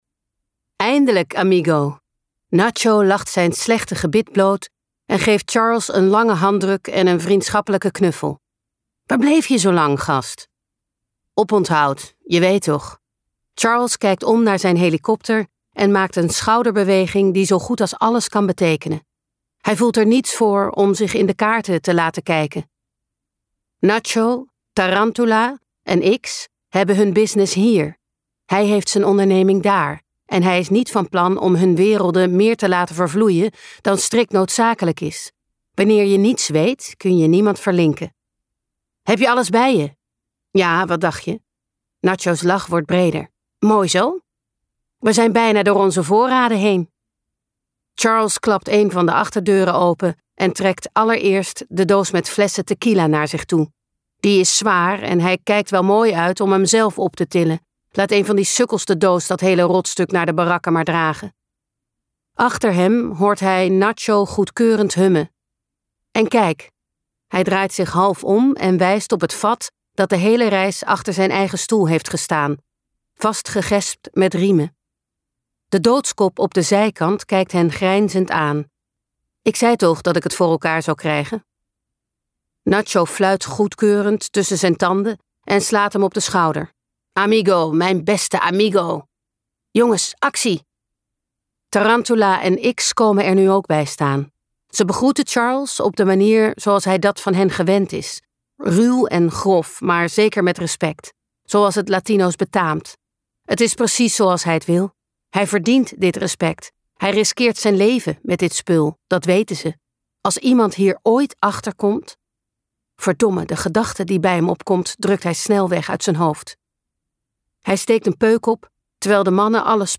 Junglekoorts luisterboek | Ambo|Anthos Uitgevers